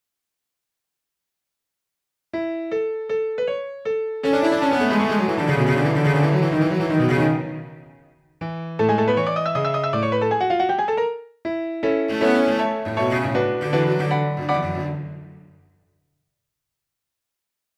The music is created by electronic sounds and instruments, which are sequenced by musicians who become the author. The sounds, therefore, do not come from an orchestra, but the result is often pleasenty surprising.